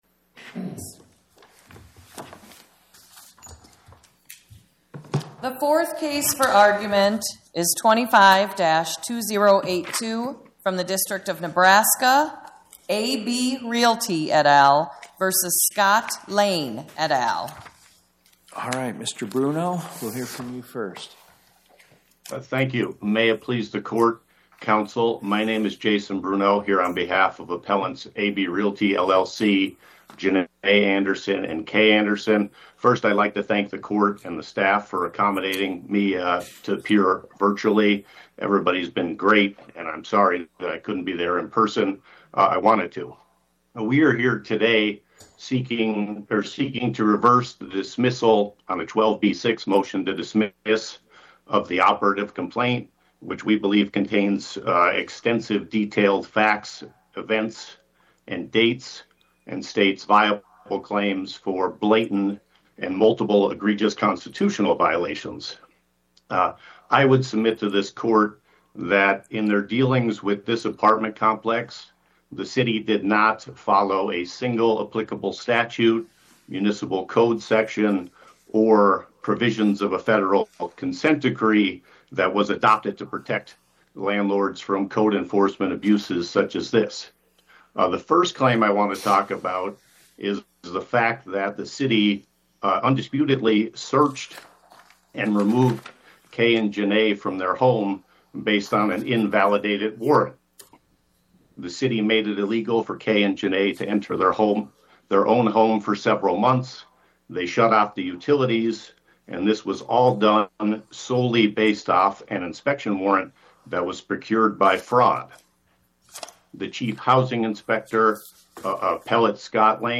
Oral argument argued before the Eighth Circuit U.S. Court of Appeals on or about 03/18/2026